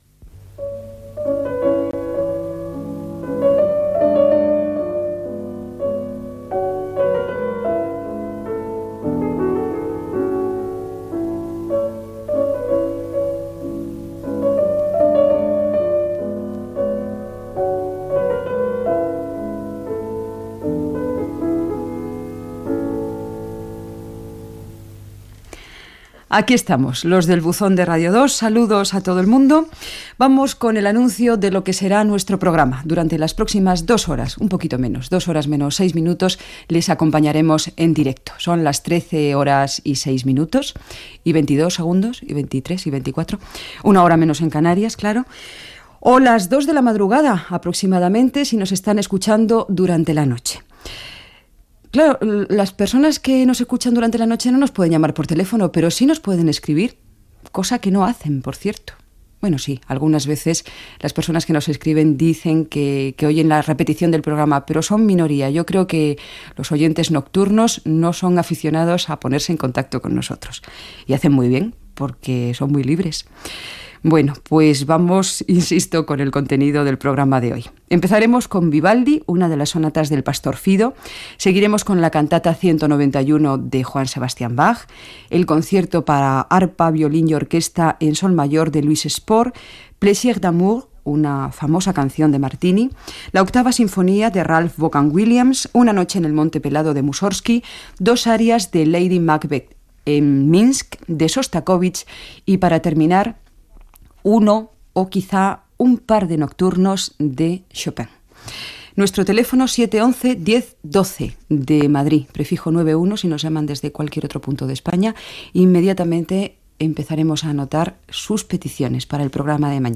Sintonia, salutació, hora, sumari, formes de contactar amb el programa, tema musical
Musical